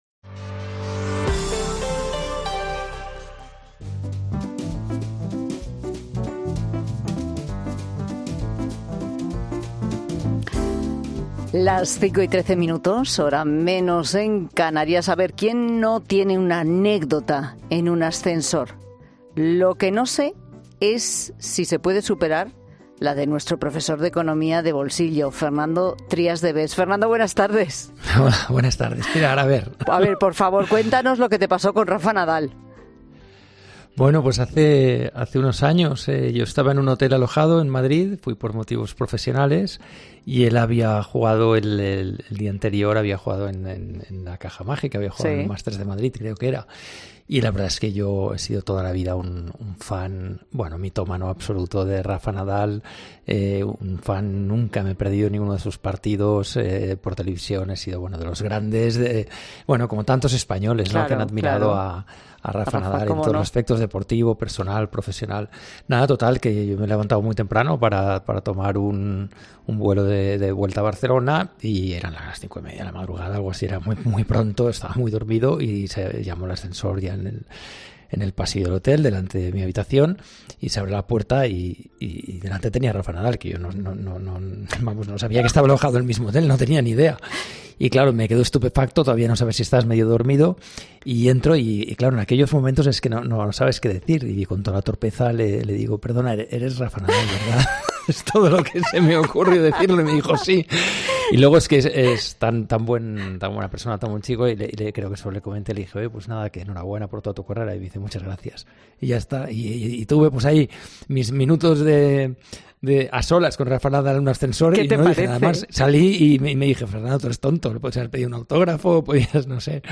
El economista Fernando Trías de Bes explica cómo afectará la normativa a las comunidades de vecinos en materia de precio y uso del ascensor